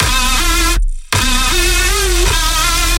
Chillax Synth Bass 80 Bmp E
描述：缓慢节奏的合成低音，是一首冷色调的曲目。用果味循环制作。如果你使用它，请告诉我
标签： 80 bpm Chill Out Loops Bass Loops 516.97 KB wav Key : E
声道立体声